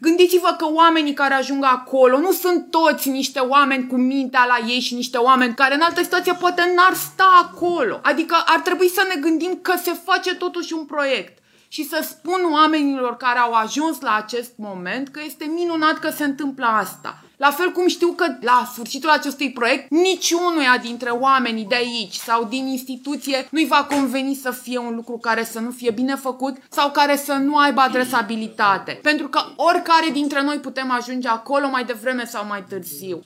Tot în cadrul ședinței, consilierii au discutat proiectul de hotărâre privind aprobarea Planului Urbanistic Zonal pentru construirea unui centru destinat îngrijirii persoanelor vârstnice, propus pe strada Viitorului din municipiu.
Claudia Frandeș (PNL) a declarat: